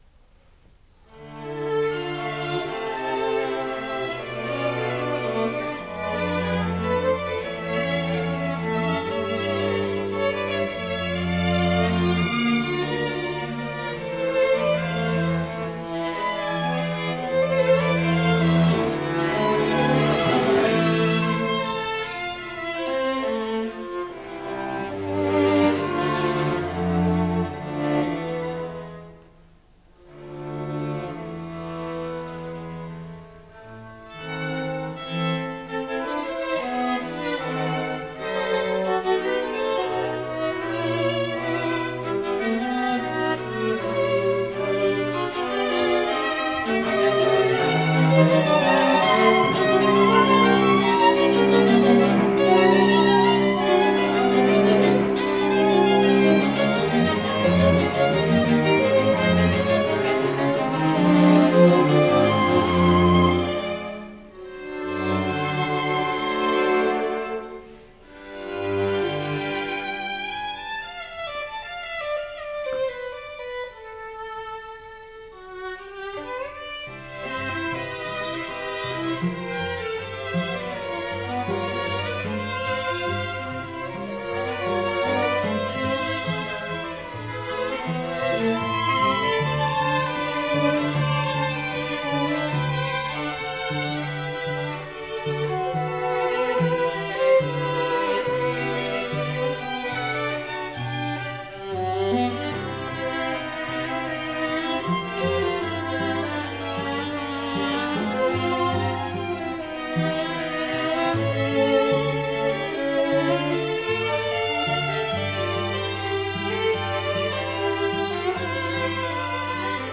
CHAMBER MUSIC CONCERT featuring students of the Shepherd School of Music Sunday, February 14, 1999 8:00 p.m. Lillian H. Duncan Recital Hall